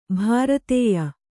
♪ bharatēya